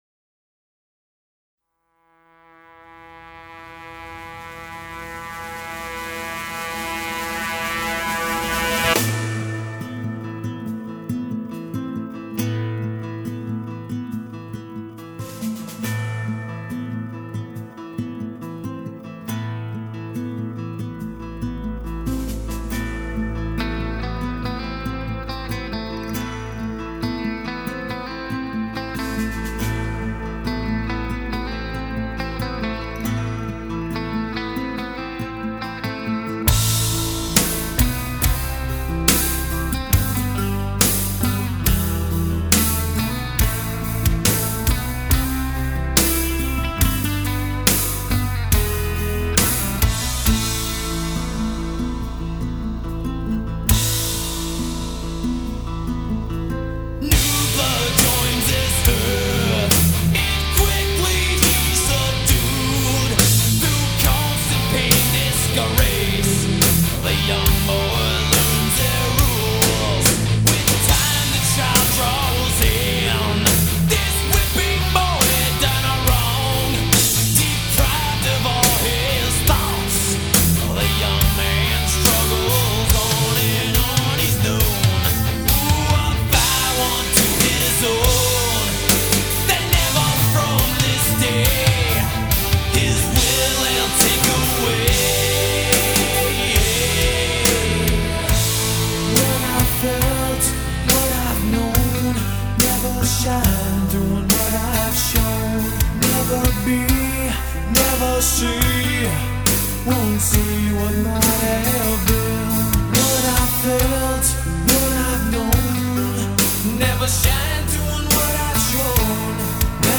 исполняющая музыку в стилях трэш-метал и хэви-метал